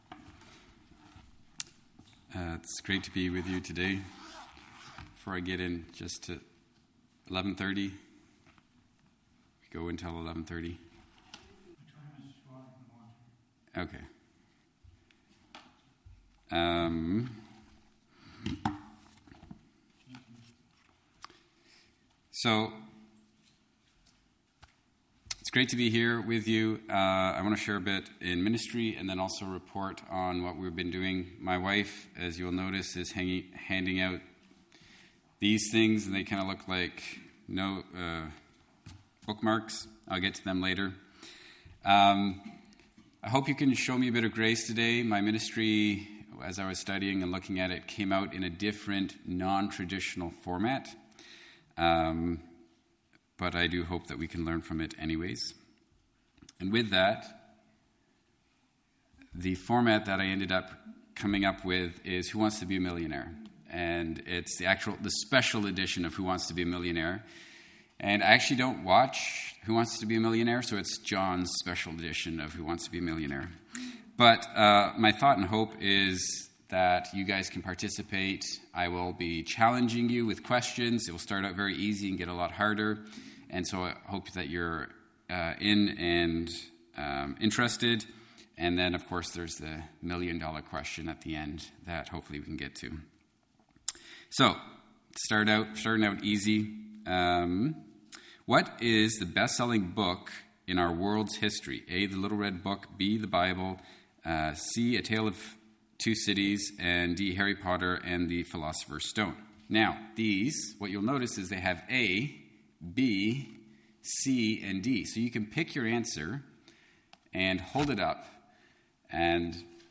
Missionary Report